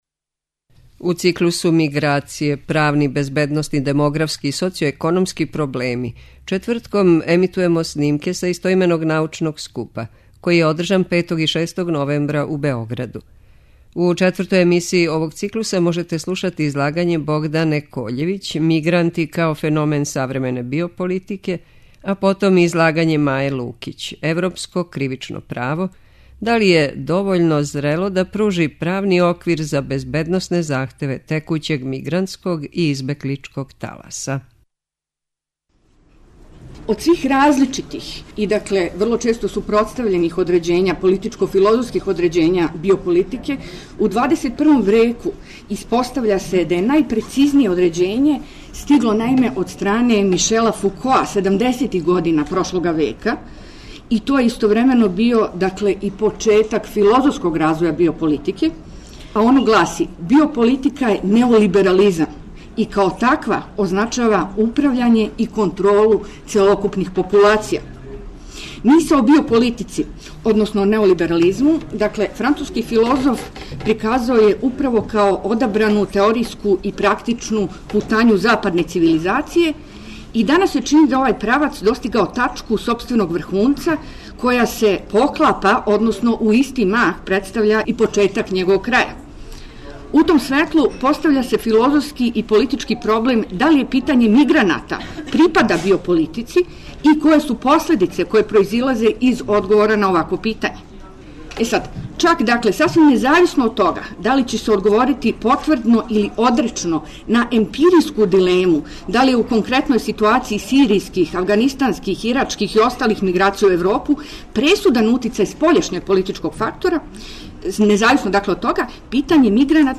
У циклусу МИГРАЦИЈЕ: ПРАВНИ, БЕЗБЕДНОСНИ, ДЕМОГРАФСКИ И СОЦИО-ЕКОНОМСКИ ПРОБЛЕМИ четвртком емитујемо снимке са истоименог научног скупа који је одржан 5. и 6. новембра у Хотелу Палас у Београду.